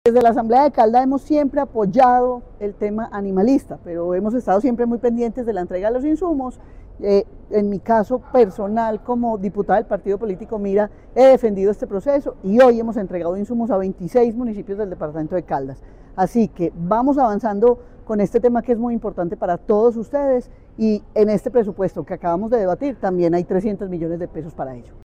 María Isabel Gaviria, diputada de Caldas.